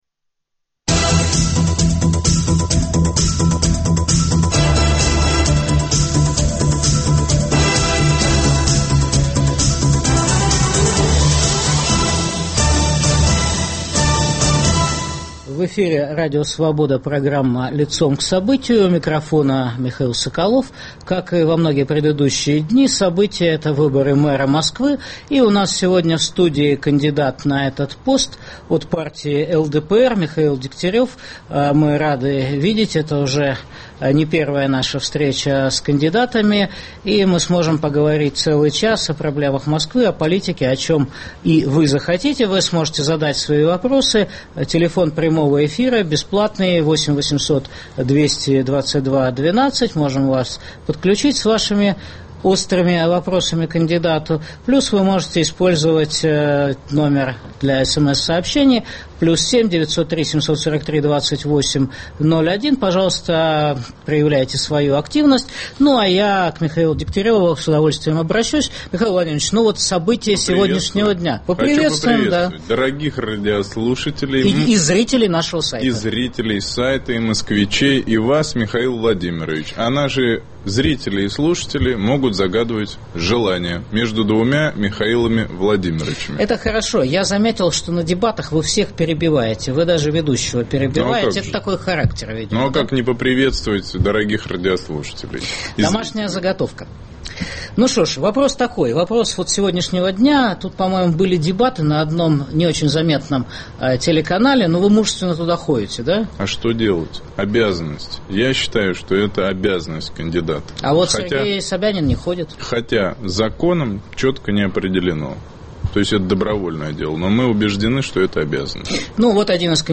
Продолжаем знакомить слушателей с кандидатами на пост мэра Москвы, их программами и планами. В программе выступит кандидат на пост мэра Москвы, депутат Госдумы России Михаил Дегтярев.